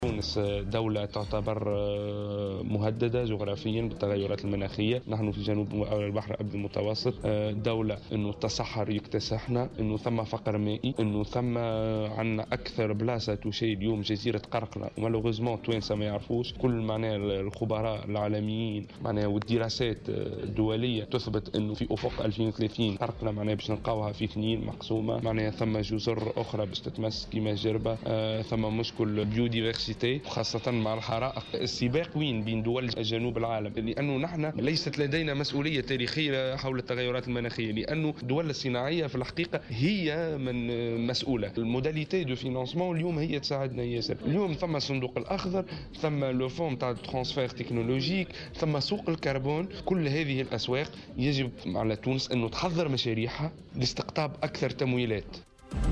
وأوضح في تصريح لمراسلة "الجوهرة أف أم" على هامش على افتتاح الملتقى الوطني حول التغيرات المناخية حول أن تونس مهدّدة بالتصحر والجفاف، مشيرا إلى أن دراسات دولية أثبتت أن جزيرة قرقنة الأكثر عرضة لتأثيرات هذه التغيرات في أفق سنة 2030.